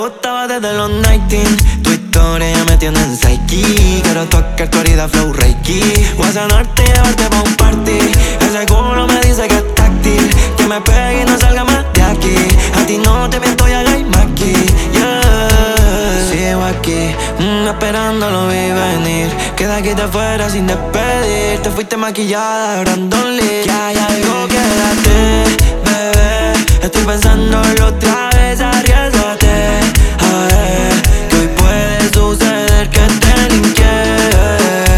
Жанр: Латино / Электроника